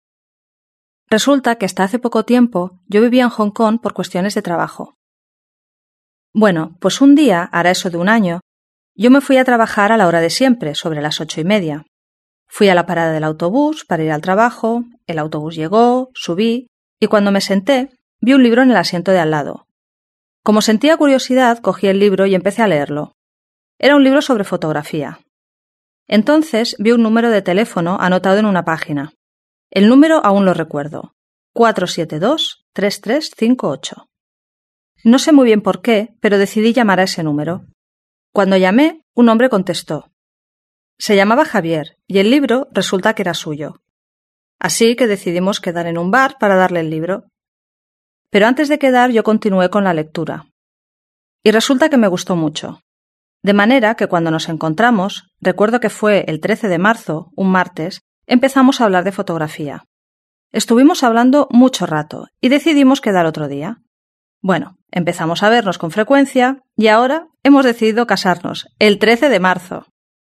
Escuche a alguien que habla sobre una vez que encontró algo.